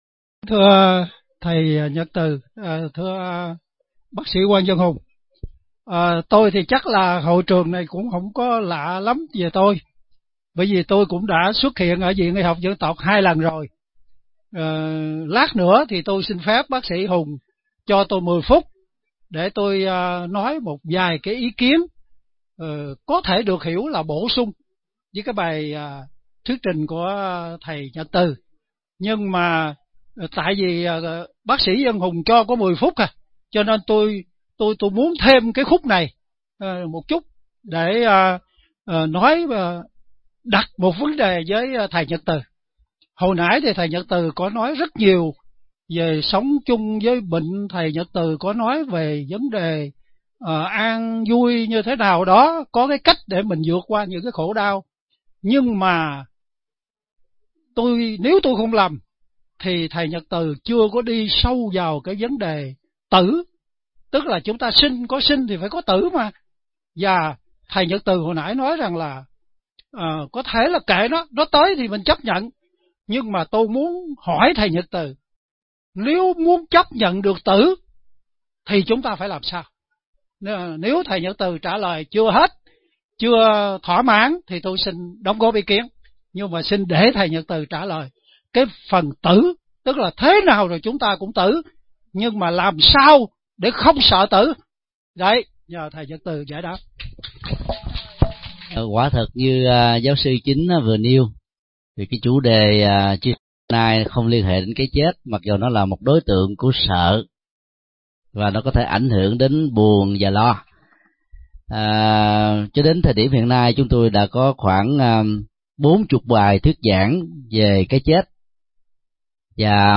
Nghe mp3 Vấn đáp